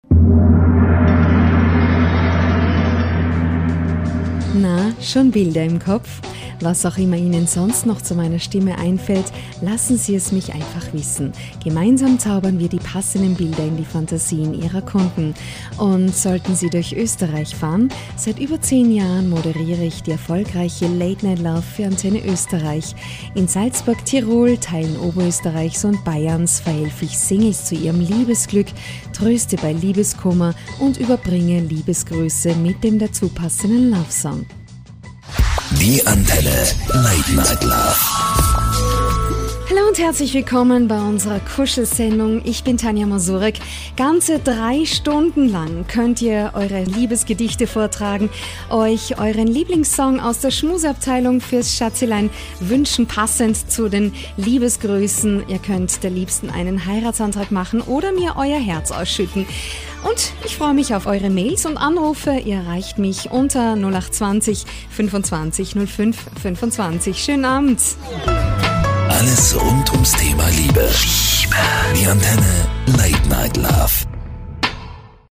Stimmbeschreibung: Warm, einfühlsam, erotisch/sexy, verführerisch, werblich, jung oder reif, elegant, sympatisch, souverän, geheimnissvoll, sachlich/kompetent, erzählerisch, selbstbewusst und wandlungsfähig.
Sprecherin und Sängerin. Stimme: Warm, einfühlsam, erotisch / sexy, verführerisch, jung oder reif, elegant, sympatisch, souverän, geheimnissvoll,
Sprechprobe: eLearning (Muttersprache):